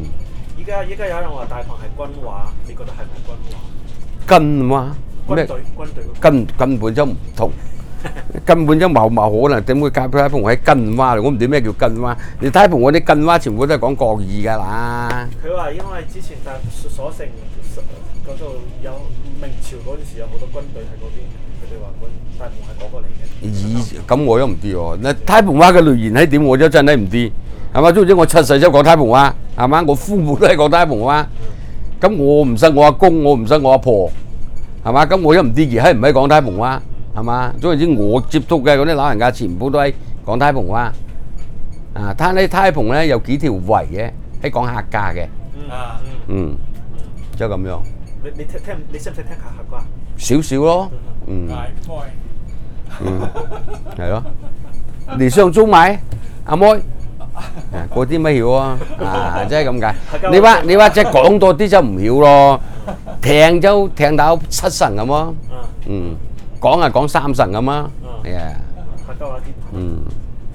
Male, 57
Male, 73
digital wav file recorded at 44.1 kHz/16 bit on Zoom H2 solid state recorder
New York City
Dapeng dialect in Shenzhen, China